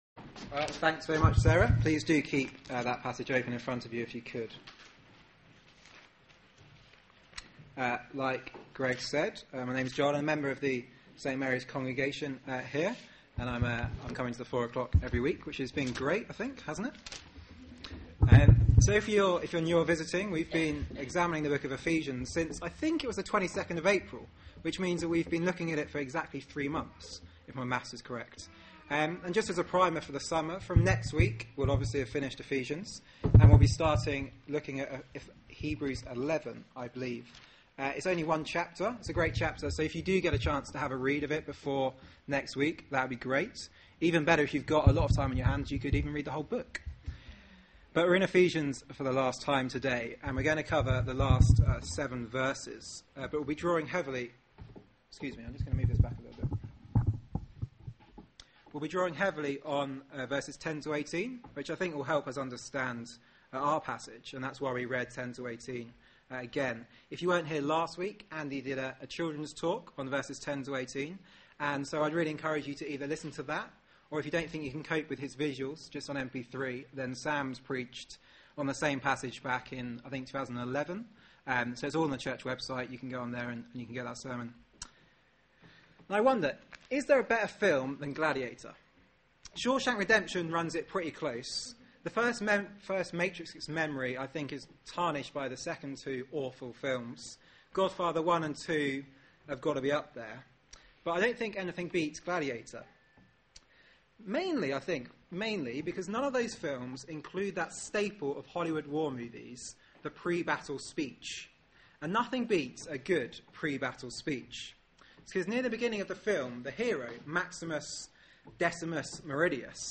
Media for 4pm Service on Sun 22nd Jul 2012 16:00 Speaker